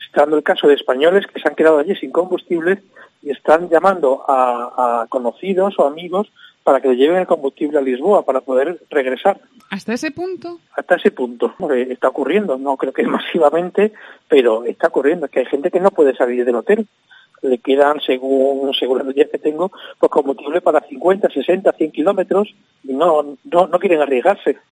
En COPE Extremadura hemos hablado con